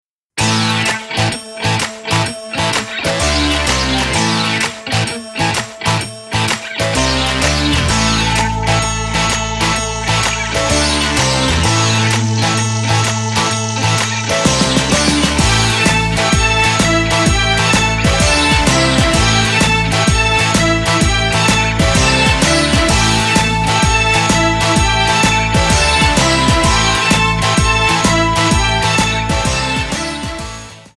MPEG 1 Layer 3 (Stereo)
Backing track Karaoke
Pop, 2000s